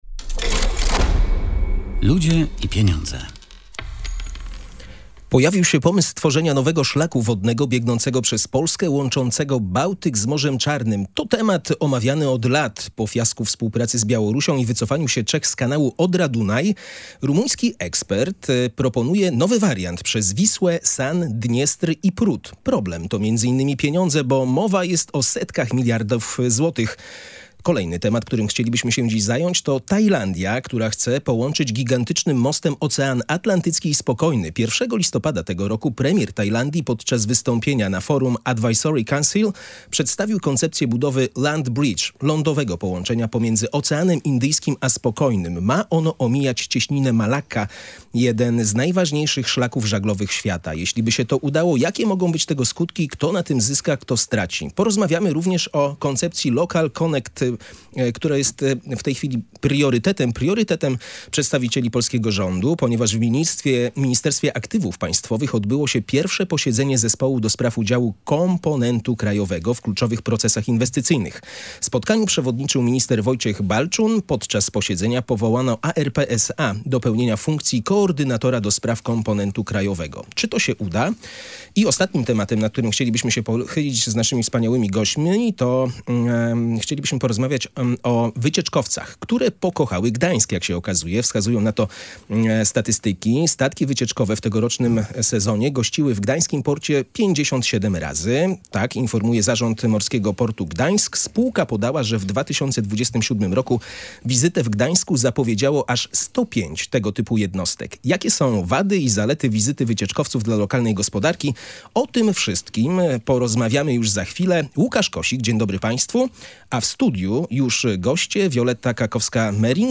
Rumuński ekspert proponuje nowy wariant przez Wisłę, San, Dniestr i Prut. Problem to między innymi pieniądze, bo mowa o setkach miliardów złotych. Na ten temat dyskutowali goście audycji „Ludzie i Pieniądze”